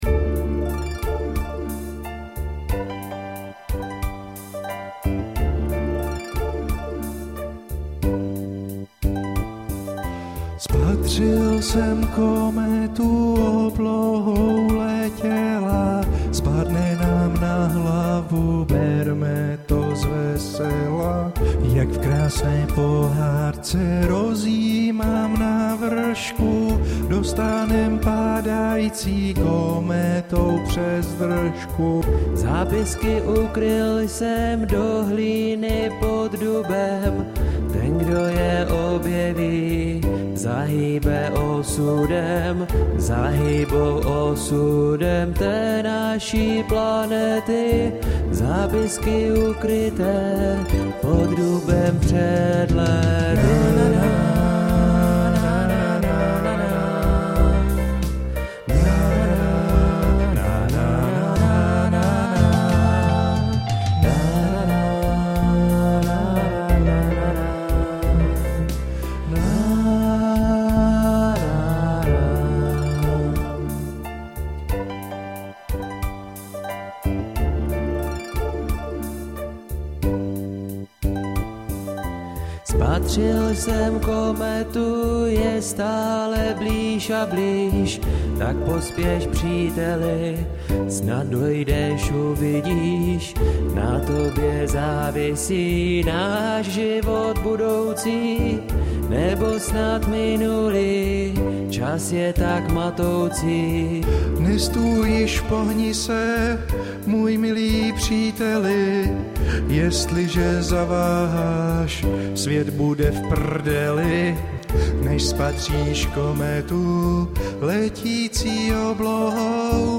A tak si hraju a zpívám a bavím se jak malý Jarda.